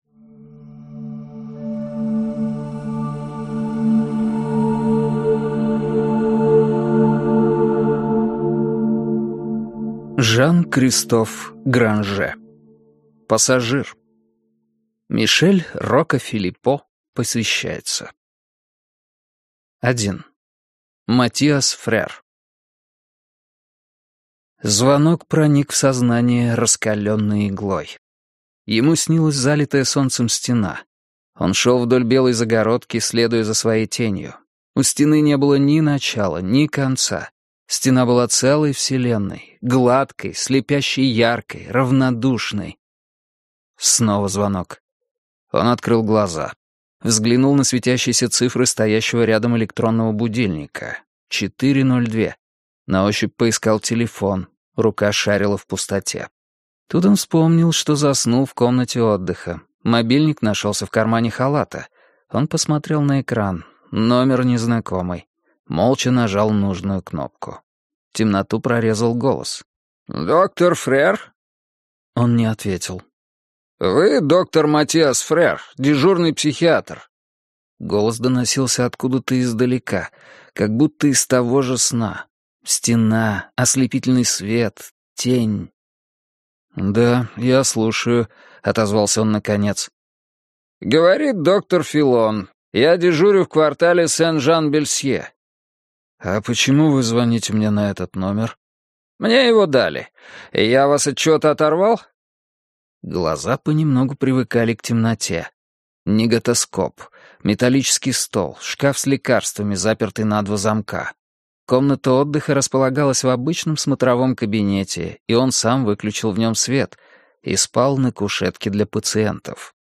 Аудиокнига Пассажир - купить, скачать и слушать онлайн | КнигоПоиск